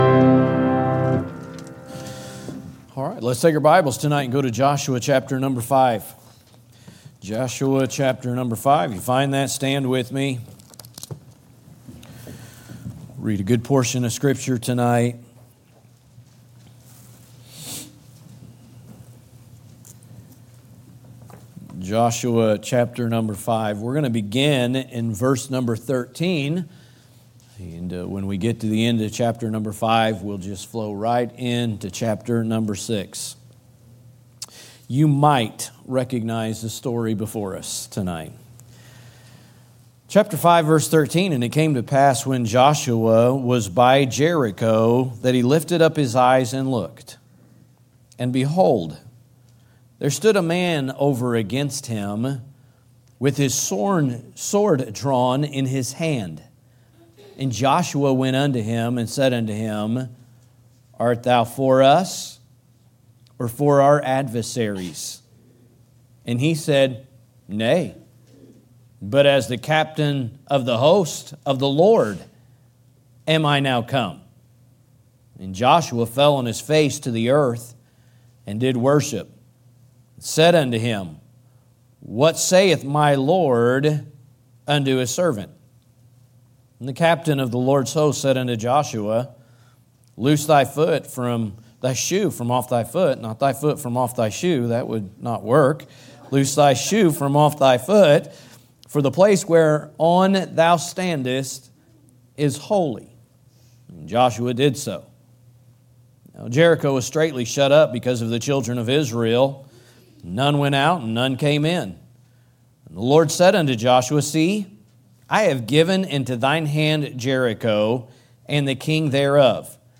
Download Watch Listen Details Share From Series: " The Victorious Christian Life " Preaching through the book of Joshua. Scripture References: Joshua 5:13-15 , Joshua 6:1-21